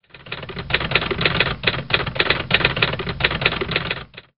Schreibmaschine
Ein Geräusch, wie es heute nur noch selten zu hören ist: das Schlagen der eisernen Lettern einer Schreibmaschine auf ein Blatt Papier. Früher waren die Büros erfüllt mir diesem Geräusch, doch mit der zunehmenden Verwendung des Computers erstummten die Schreibmaschinen.